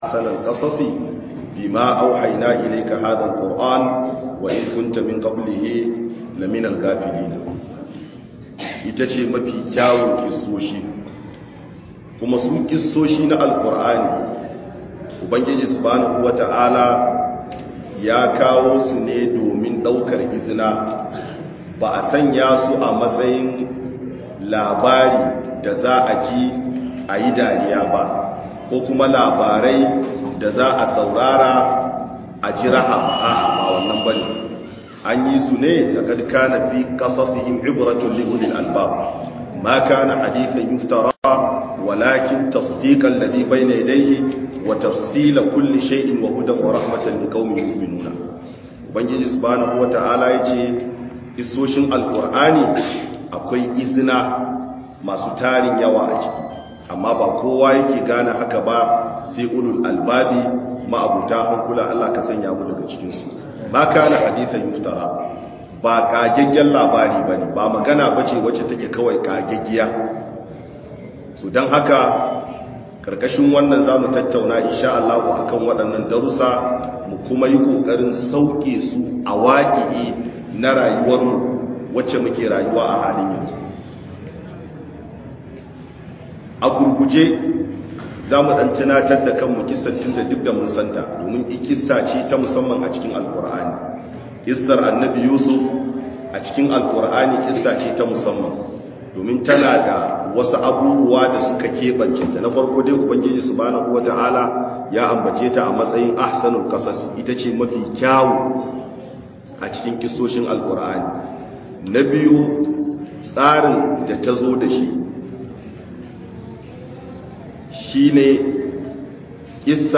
Book Muhadara